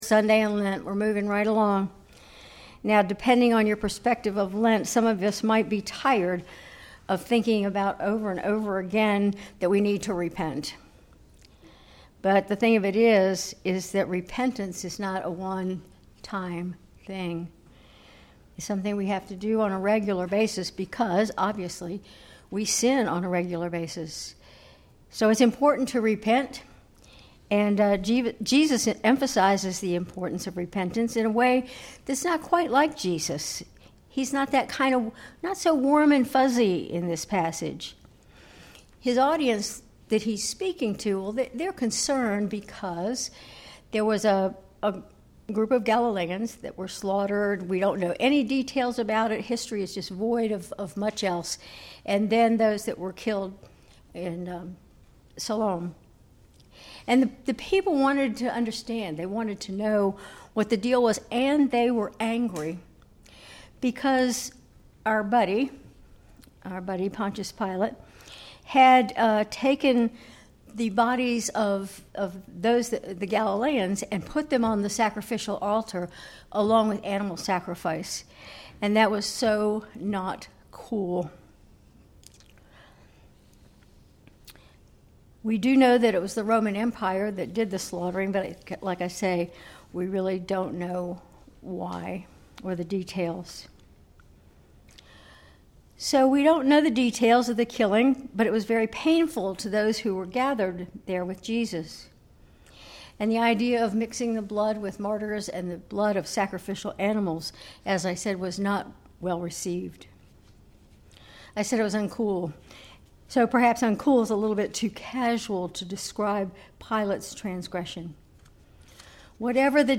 Sermon March 23, 2025